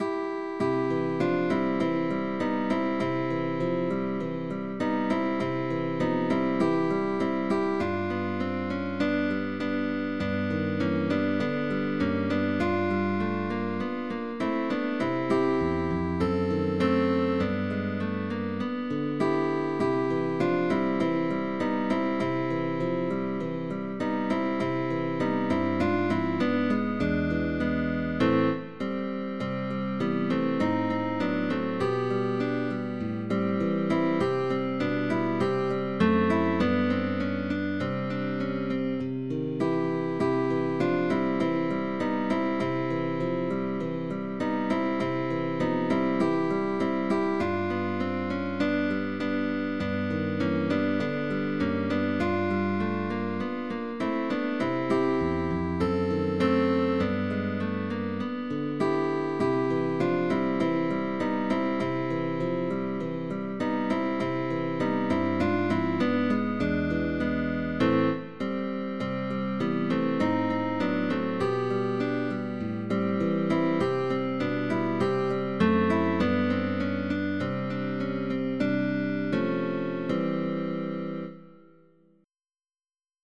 GUITARRA MELÓDICA. 2 ALUMNOS Y PROFESOR
Canción infantil americana
Partitura para dos alumnos con acompañamiento del profesor.